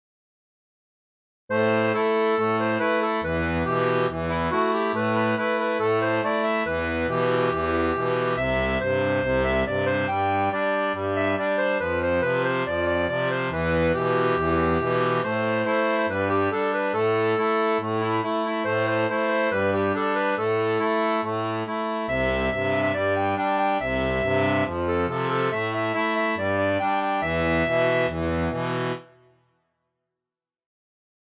• Une tablature pour diato 2 rangs transposée en La
Chanson française